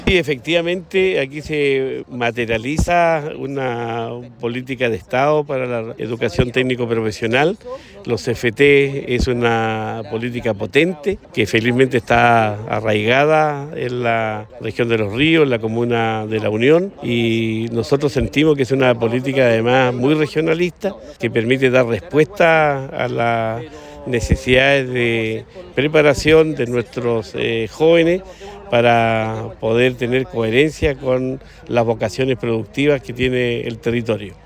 Gobernador-Regional-Luis-Cuvertino.mp3